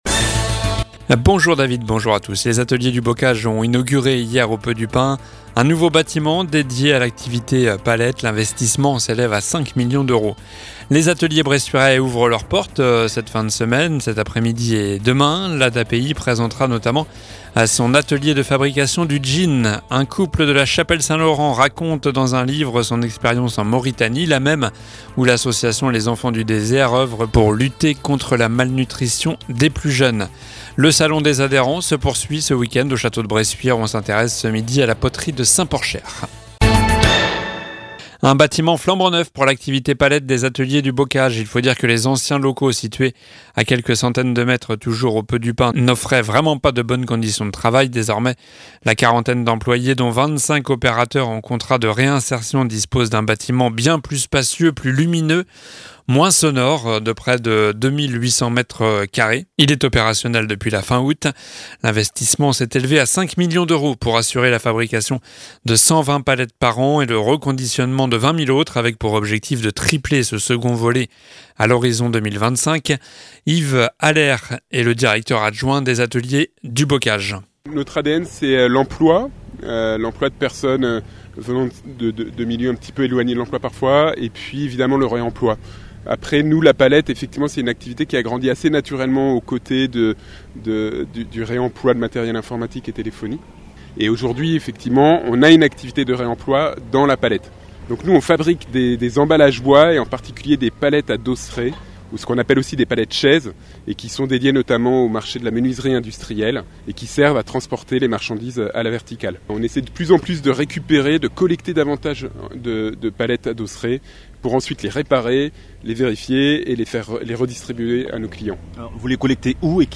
Journal du vendredi 13 octobre (midi)